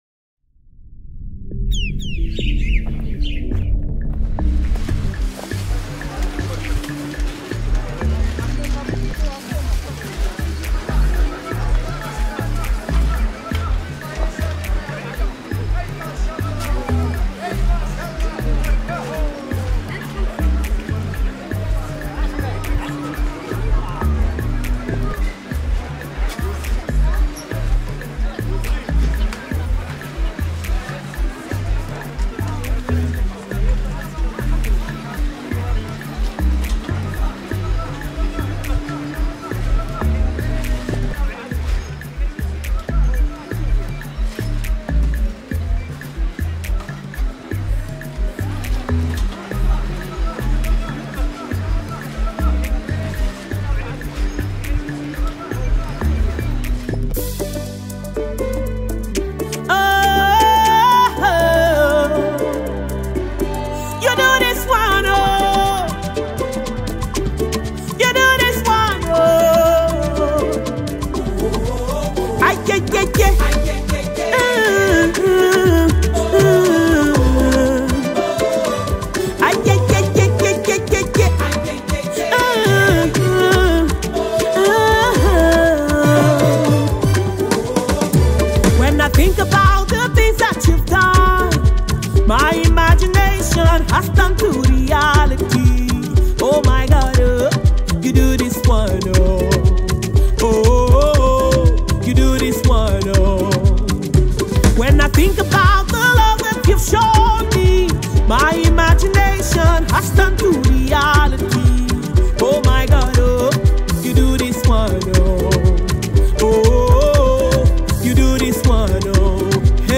studio performance
Through her soulful musical creation